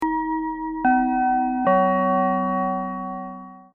Dienstmededeling DING DONG DING!!!!!(<-klik!!)
announcement.mp3